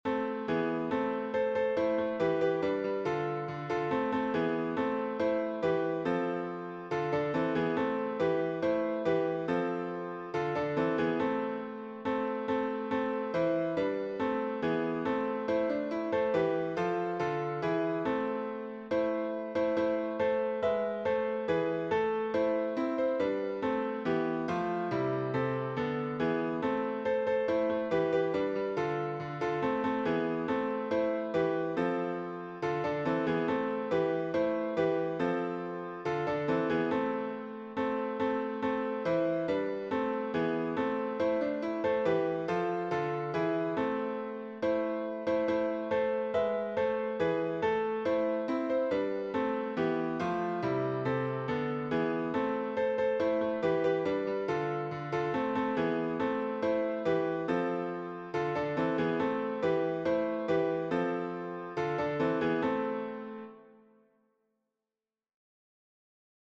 - chant pour chœur mixte à 3 voix (SAH)
MP3 version piano
Tutti